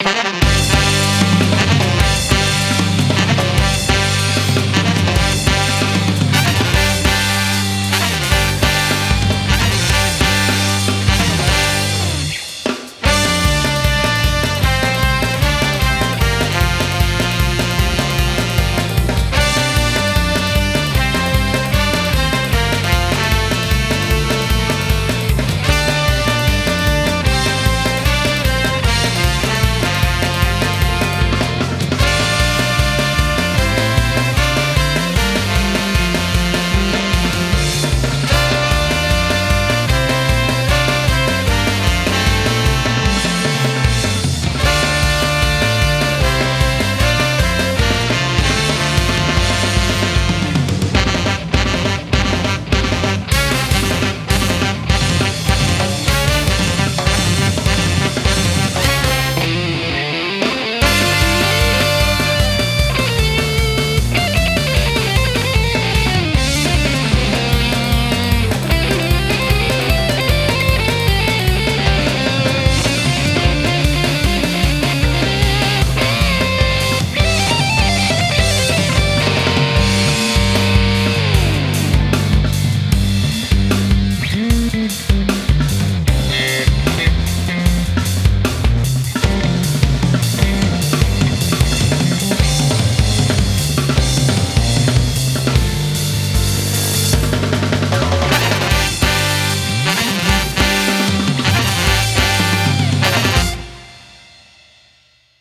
BPM304
Audio QualityPerfect (High Quality)
It's a banger trust me.